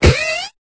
Cri de Moufouette dans Pokémon Épée et Bouclier.